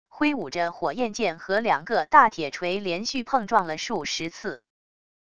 挥舞着火焰剑和两个大铁锤连续碰撞了数十次wav音频